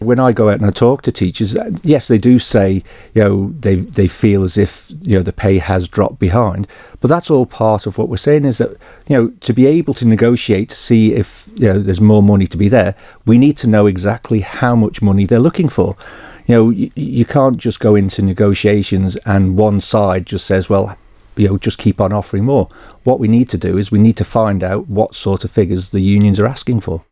But he says he also needs to know what exactly is being asked for: